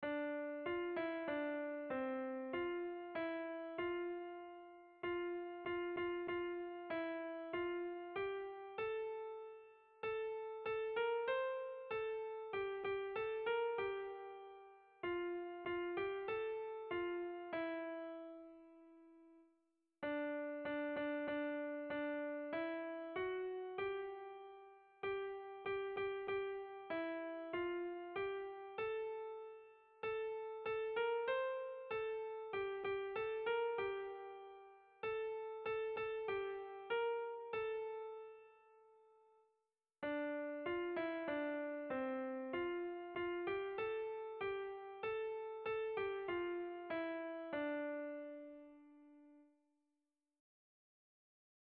Kontakizunezkoa